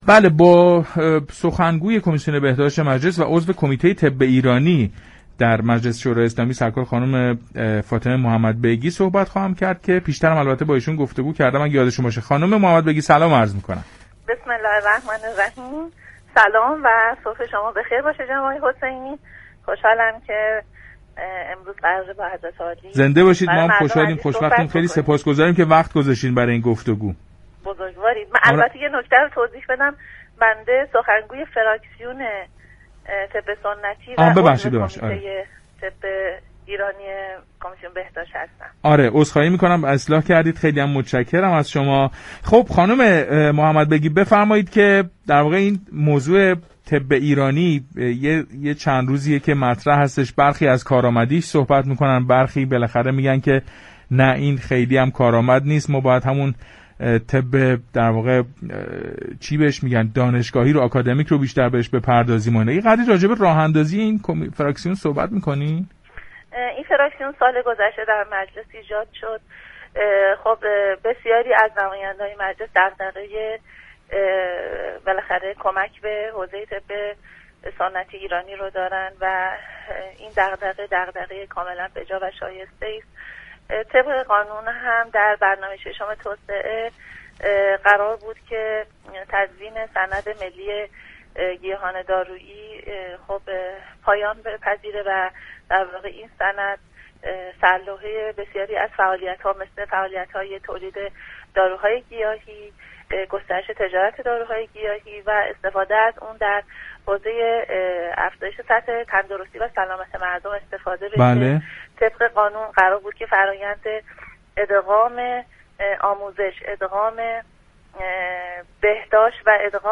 در گفتگو با برنامه پارك شهر رادیو تهران